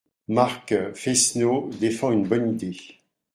/maʁk/